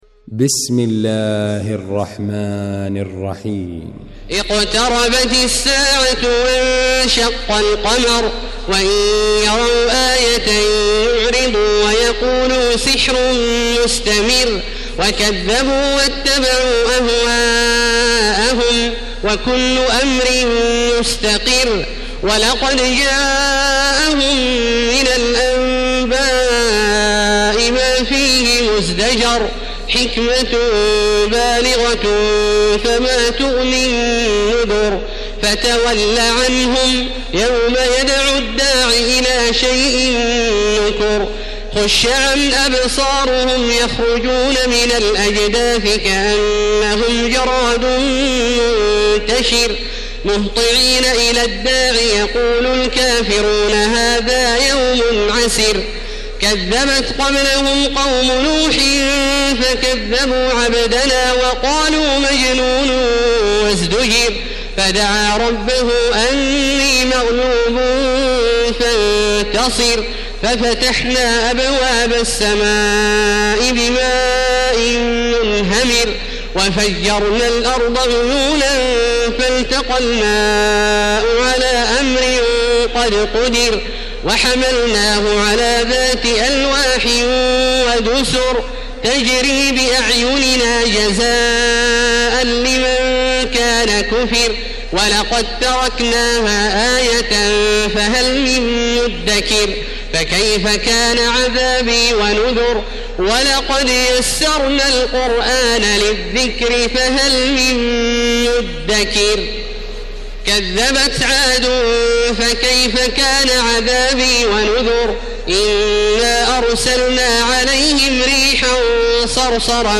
المكان: المسجد الحرام الشيخ: فضيلة الشيخ عبدالله الجهني فضيلة الشيخ عبدالله الجهني القمر